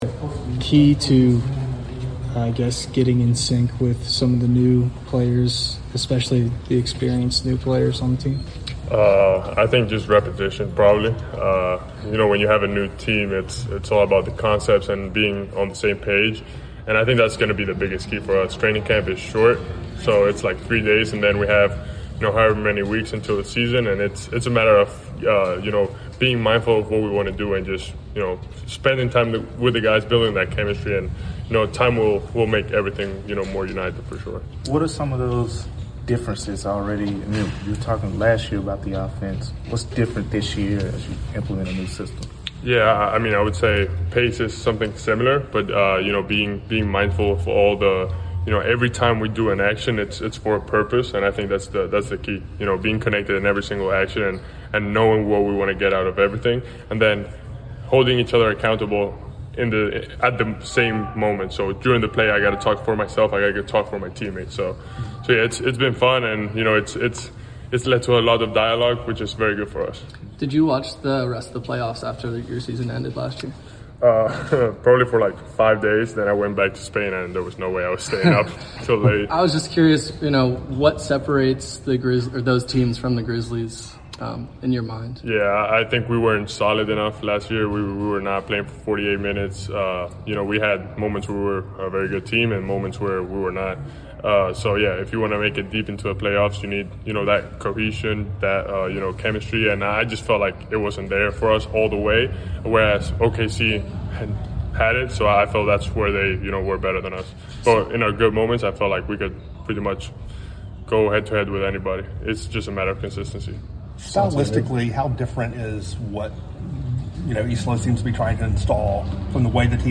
Memphis Grizzlies Forward Santi Aldama Press Conference after the first day of Training Camp.